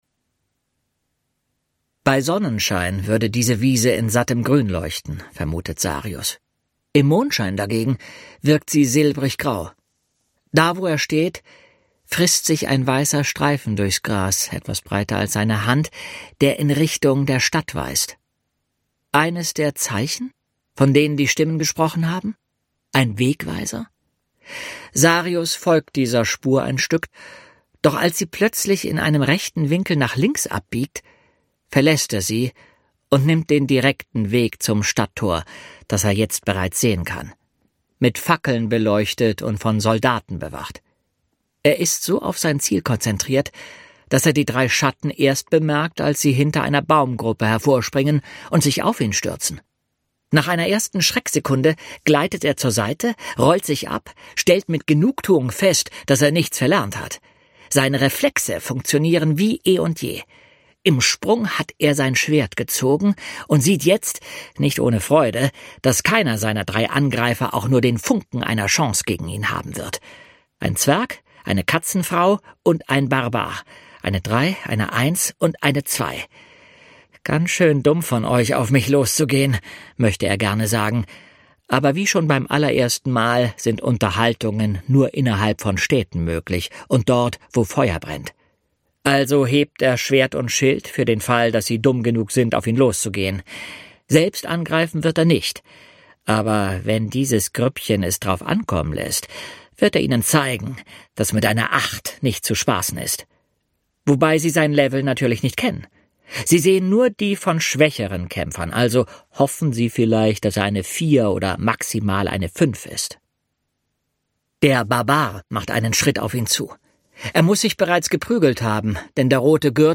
Jens Wawrczeck (Sprecher)
ungekürzte Lesung